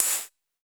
Index of /musicradar/ultimate-hihat-samples/Hits/ElectroHat A
UHH_ElectroHatA_Hit-11.wav